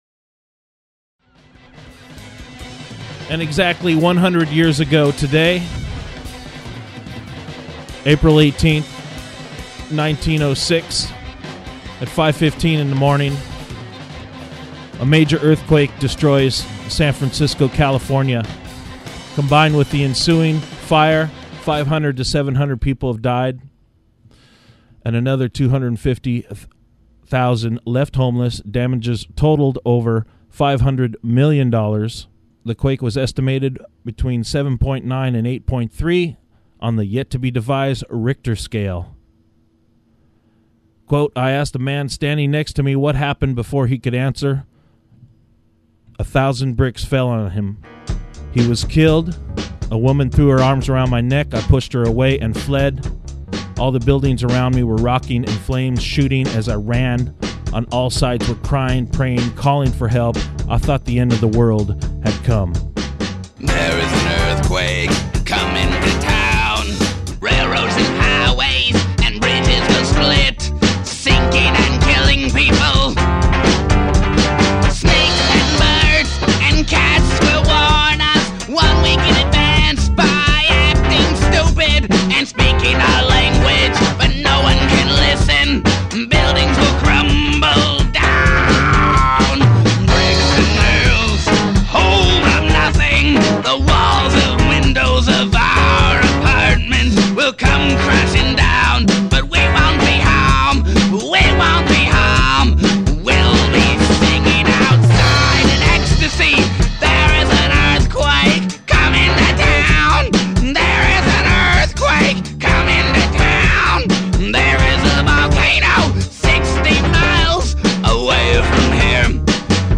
Operation Ear Infection Segment: 100 Years Ago Today: San Fransisco Earthquake 04-18-06 music and audio